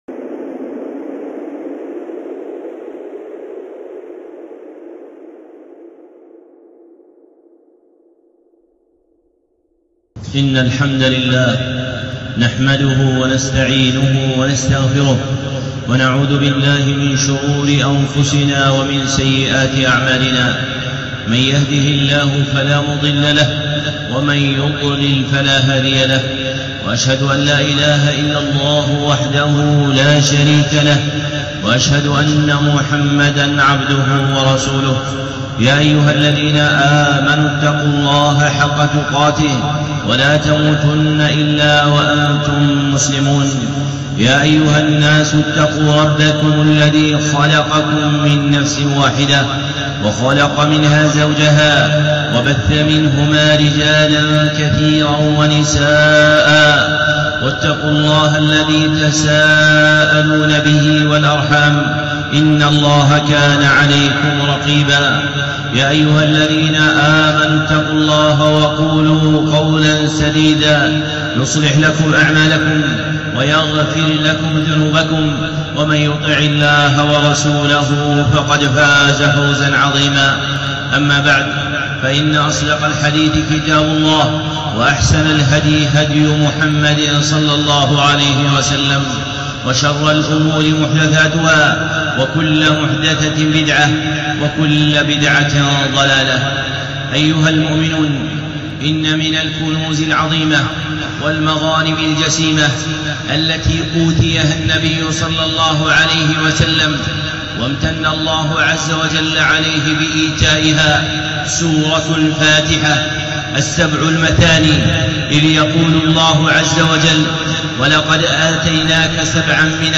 الخطب المنبرية